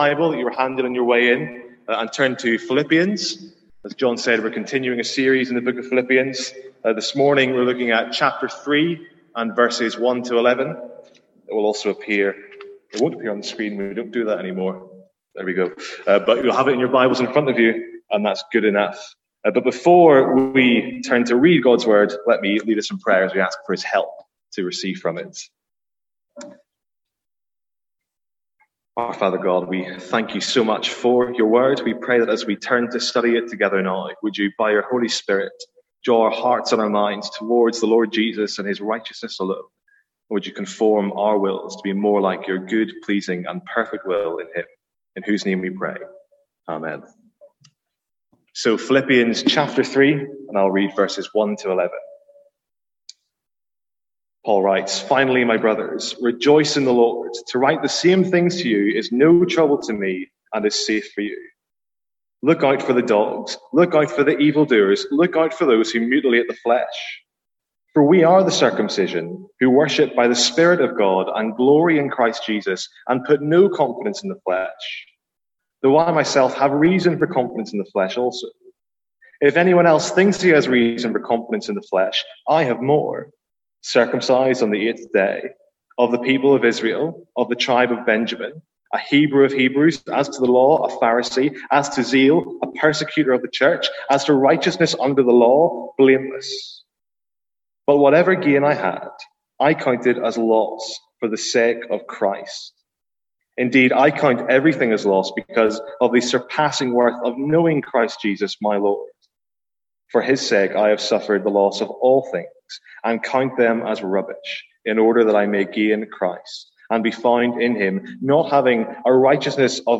From our morning series in Philippians